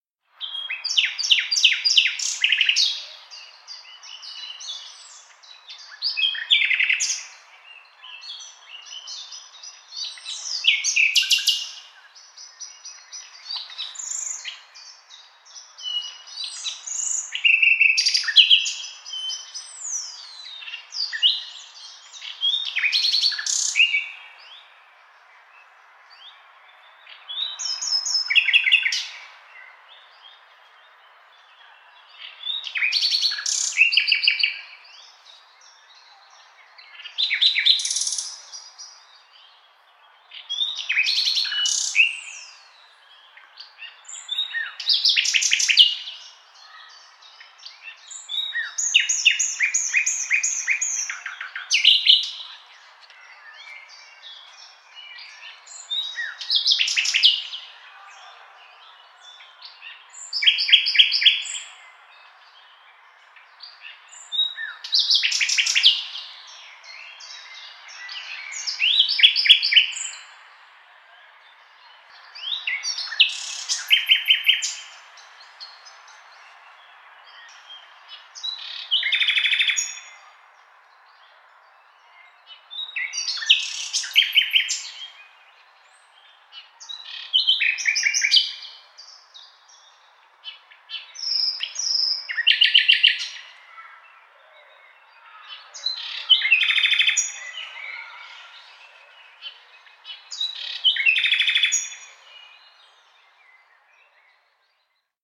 دانلود صدای بلبل در باغ از ساعد نیوز با لینک مستقیم و کیفیت بالا
جلوه های صوتی
برچسب: دانلود آهنگ های افکت صوتی انسان و موجودات زنده